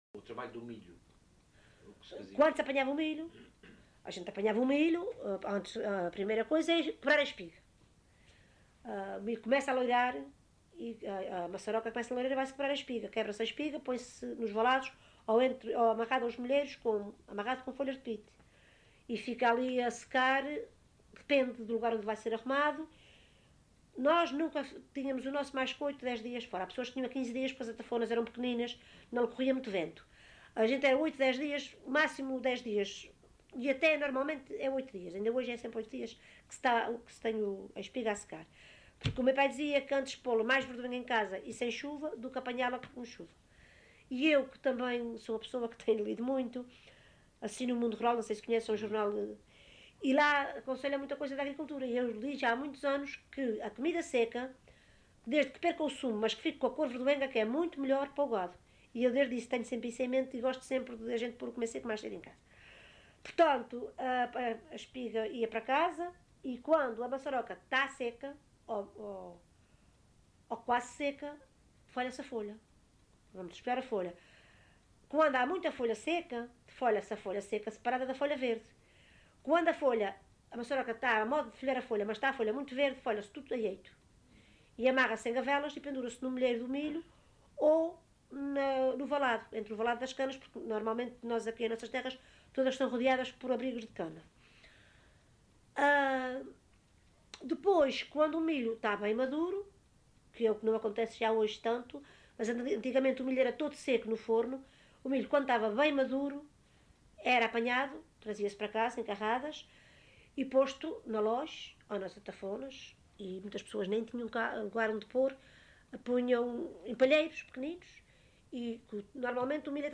LocalidadeCedros (Horta, Horta)